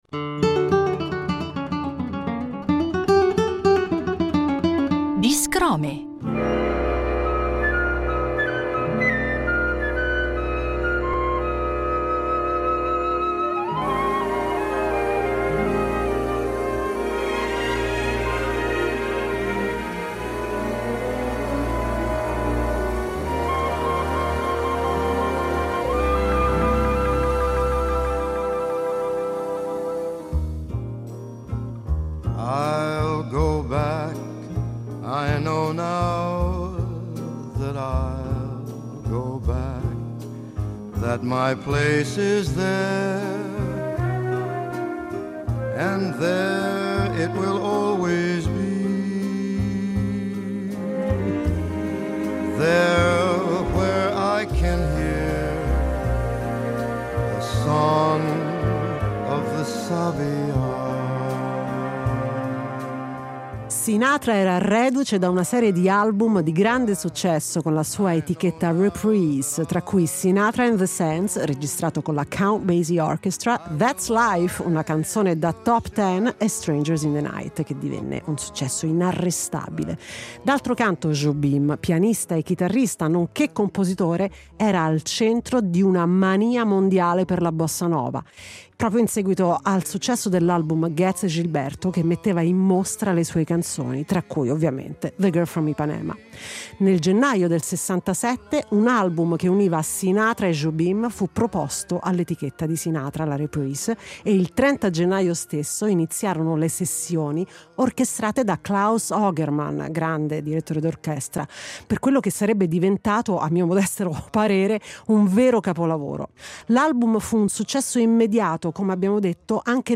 un tributo in chiave jazz